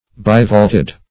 Bivaulted \Bi*vault"ed\, a.